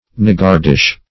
Niggardish \Nig"gard*ish\, a. Somewhat niggard.